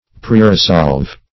Search Result for " preresolve" : The Collaborative International Dictionary of English v.0.48: Preresolve \Pre`re*solve"\, v. t. & i. [imp.